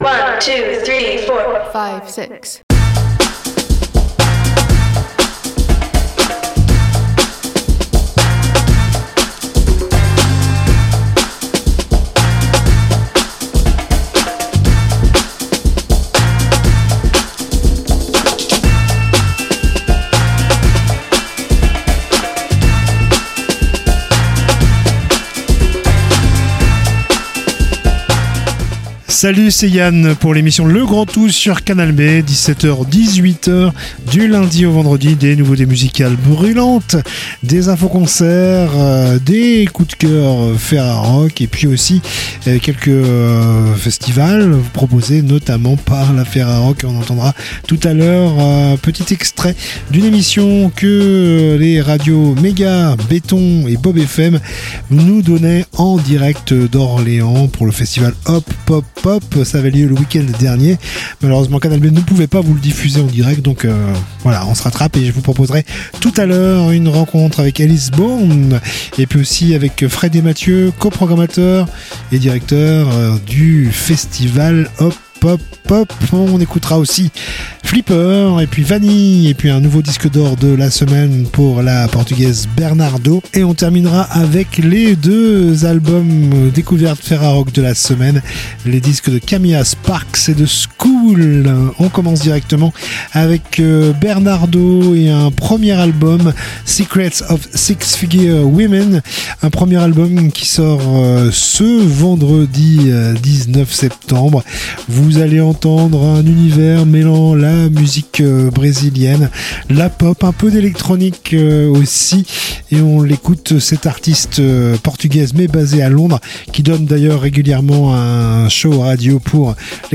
L'équipe de la Férarock , constituée de membres de Radio Méga , Radio Béton et Beaub FM a réalisé les 12 et 13/09 des émissions à Orléans en direct du festival Hop Pop Hop !